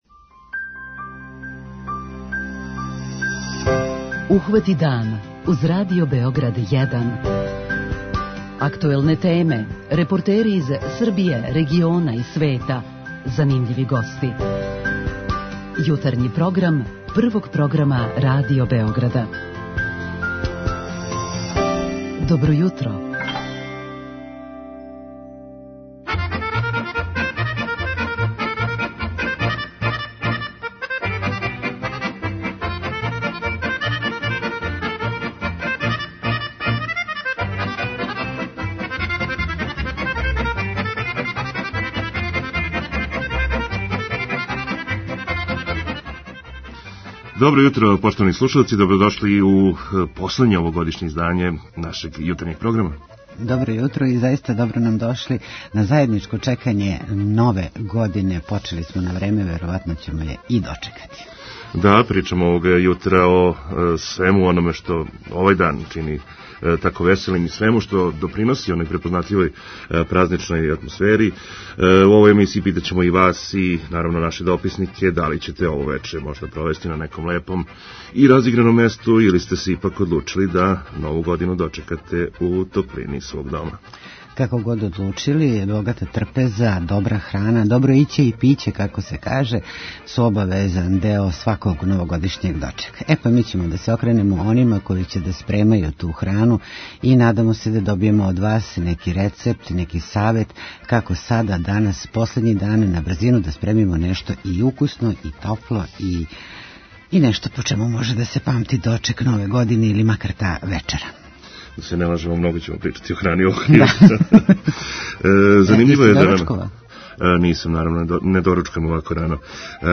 Наше, последње овогодишње јутро, посветићемо баш тим, нашим слушаоцима који су - посвећени храни у овим празничним данима. Позивамо и вас да нам помогнете у тој причи: ваши рецепти и кулинарски савети су добродошли, а за све оне који нам се јаве - обезбедили смо вредне поклоне. Наши дописници из градова који организују дочеке на улицама ће нам јавити ко ће све наступати, а све ће бити "обојено" музиком сниманом у студијима Радио Београда.